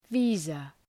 Προφορά
{‘vi:zə}